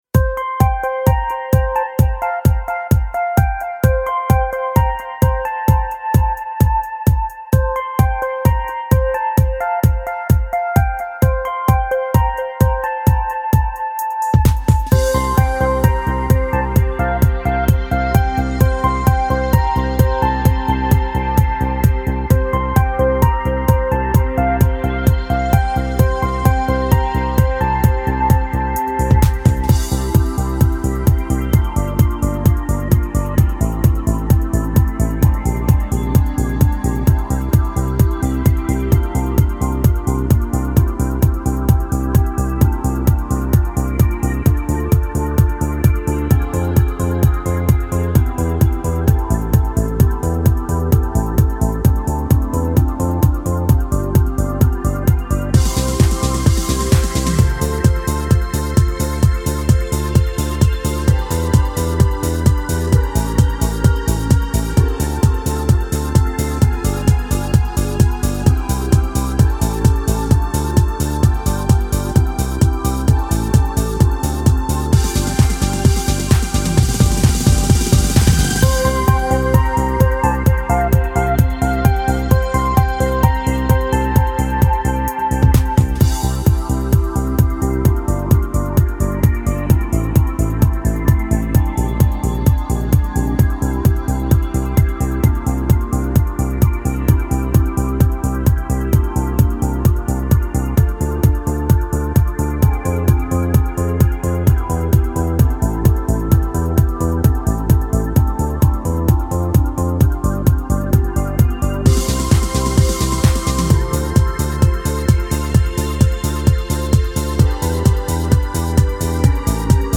• Категория: Детские песни
Слушать минус